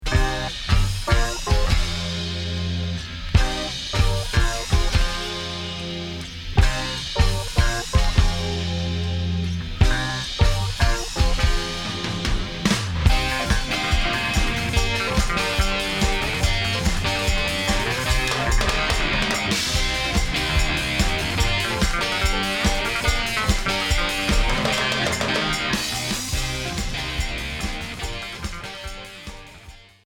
Progressif Unique 45t retour à l'accueil